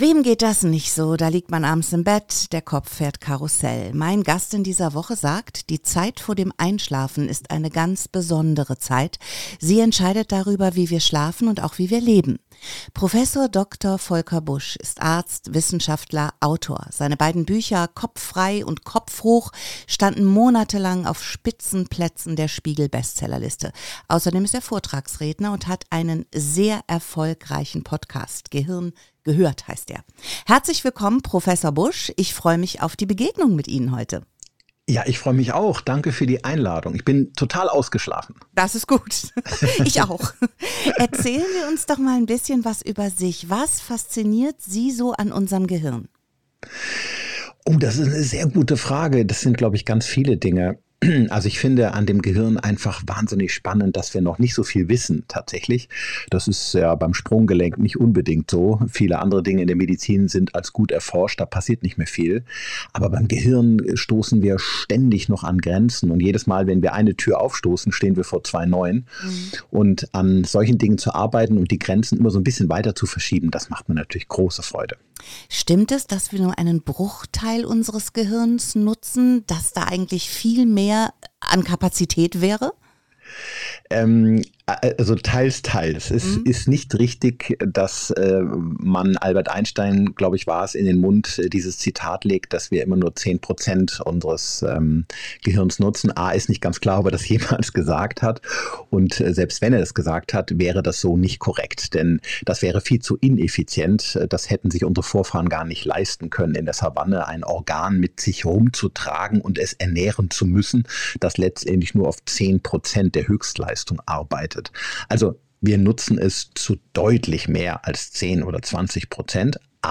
Es geht um den wertvollen Raum zwischen Tag und Nacht, um unser Gehirn, das täglich Erstaunliches leistet, und um Tipps für richtig guten Schlaf. Buch zum Interview